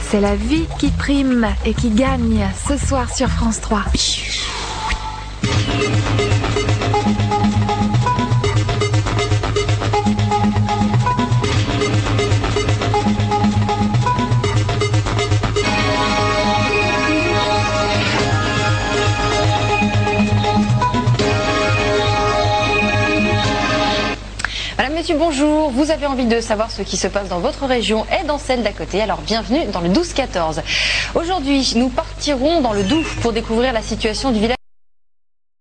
Le signal provient d'un magnétoscope.
oui, la qualité est meilleure!! d'ailleurs, je ne connaissais pas cette Edition en plateau avec une présentatrice.
oui, apparemment, c'est depuis septembre qu'il y a une Edition en plateau, et c'est réalisé à Lyon.